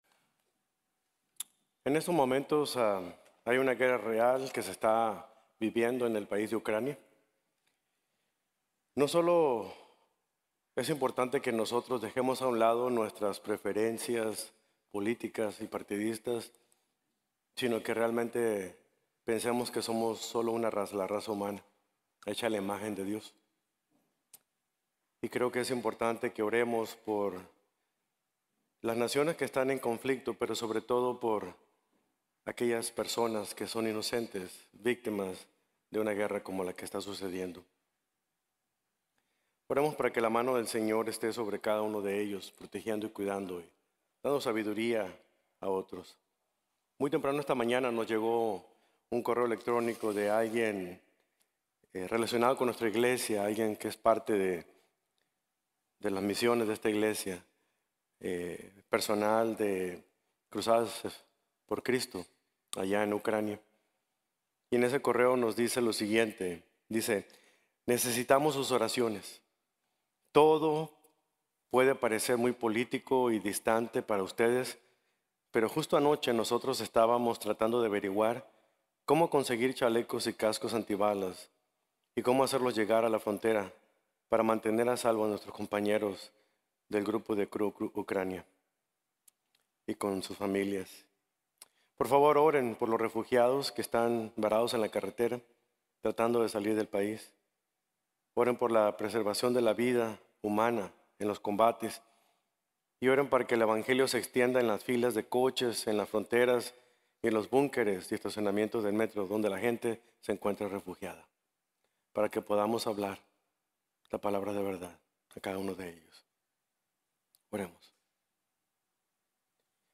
Colosenses 3:1-11 | Sermón | Iglesia Bíblica de la Gracia